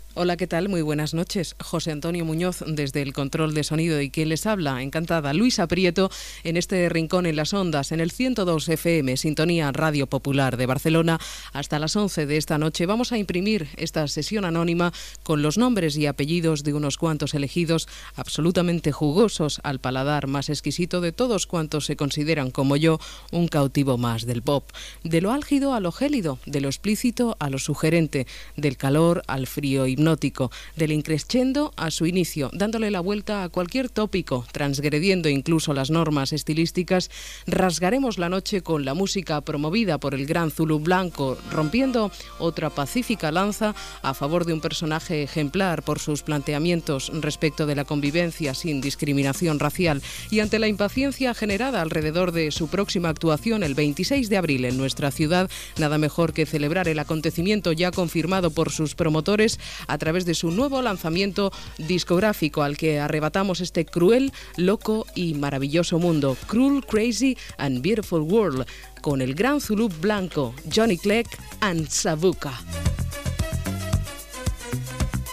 Presentació inicial del programa i tema musical
Musical
FM